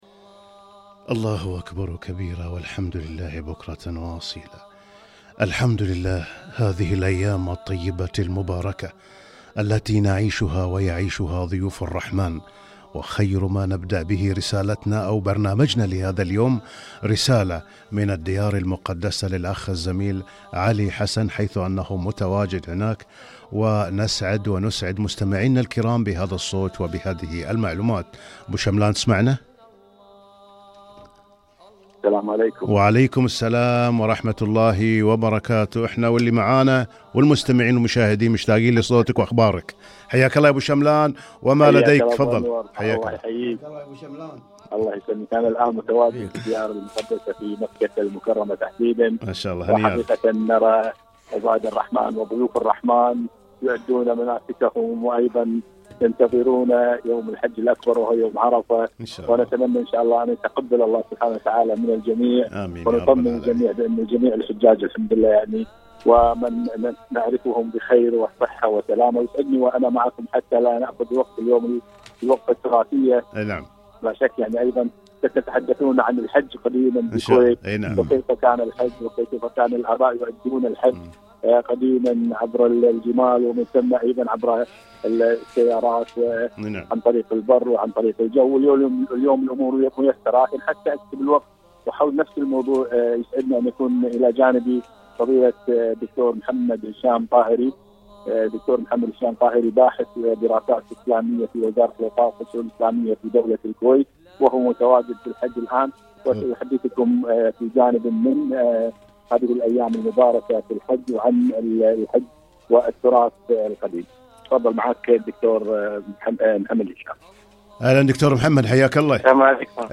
لقاء برنامج واحة المستمعين ( الحج )